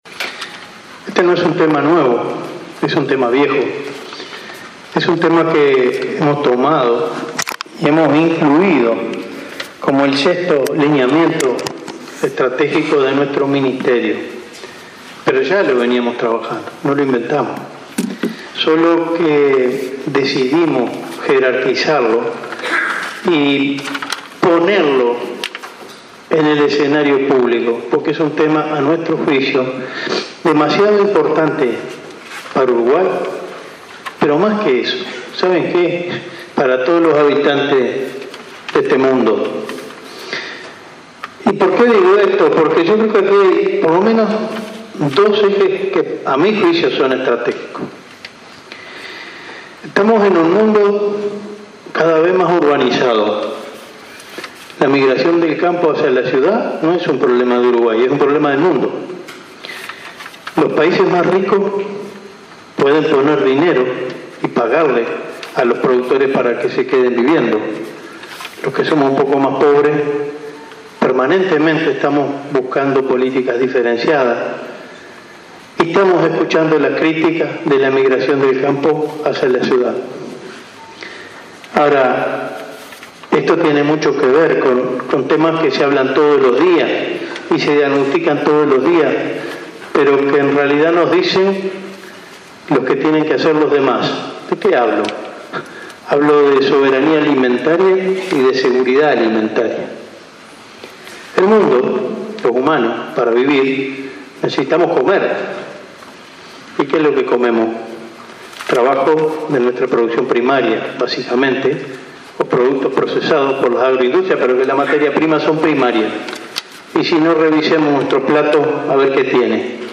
Preservar la población rural asegurándole oportunidades de desarrollo laboral con políticas diferenciadas y cuidar la soberanía y seguridad alimentaria son ejes para generar conciencia agropecuaria. Así lo subrayó el ministro de Ganadería, Enzo Benech, en la apertura del seminario sobre innovación para la conciencia agropecuaria este lunes en Montevideo.